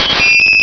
sovereignx/sound/direct_sound_samples/cries/spinarak.aif at master